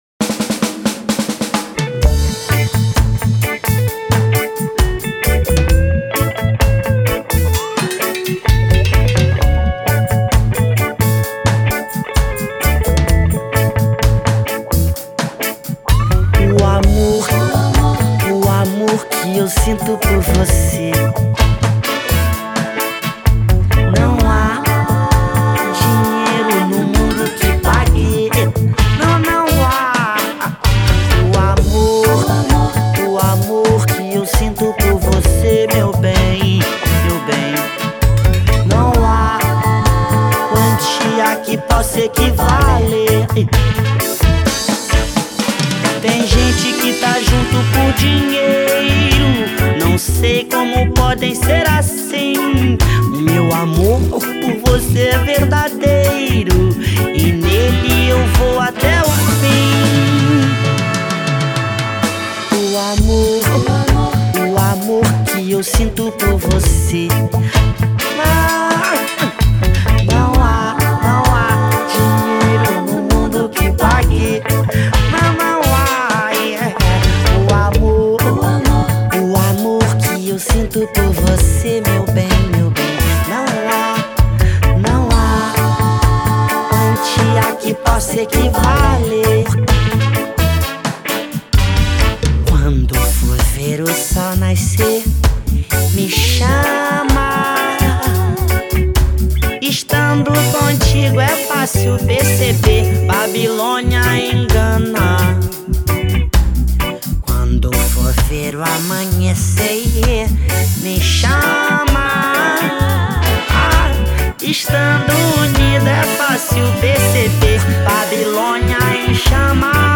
Baterista e Backing Vocal
Guitarra solo e Backing Vocal
Baixos
Guitarra base
Tambores e percussão
Tecladista